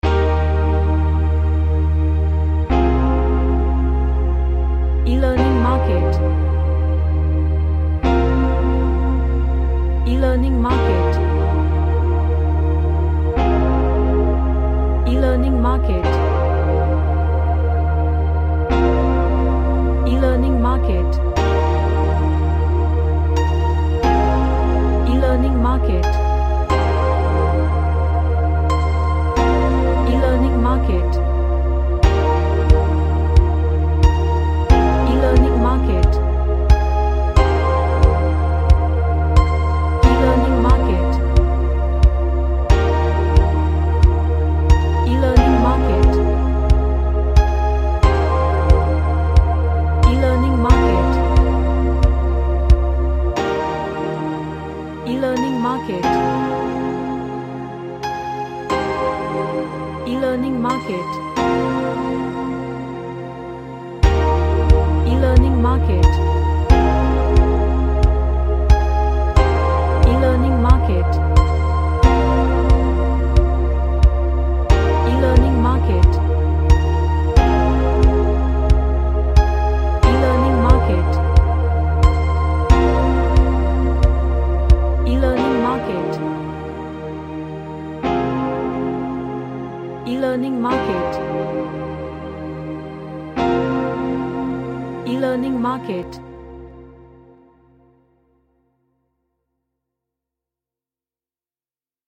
A choir based track with ambient piano.
Relaxation / Meditation